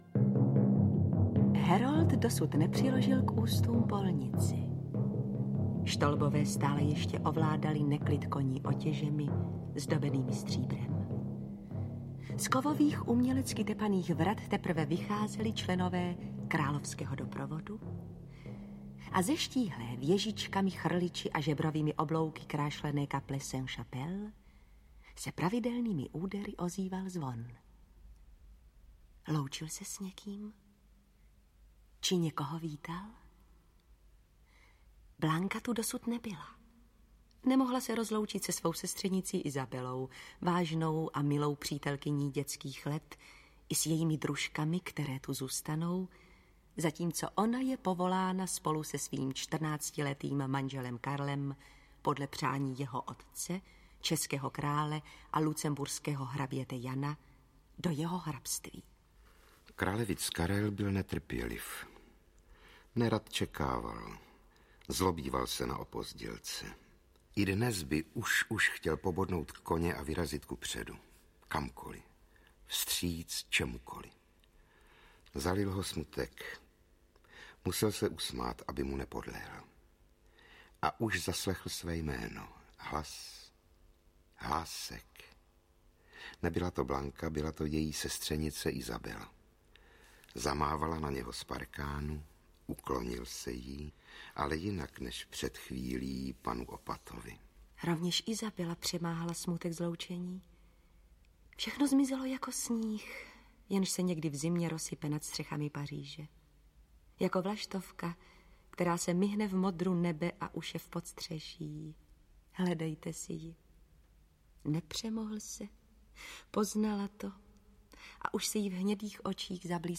Kralevic, král, císař audiokniha
Zaposlouchejte se do vyprávění, provázeného melodiemi, které by ve vás mělo vyvolat ovzduší, náladu onoho dávného 14. století, jemuž se někdy říkává podzim středověku.
Ukázka z knihy
• InterpretRůžena Merunková, Rudolf Hrušínský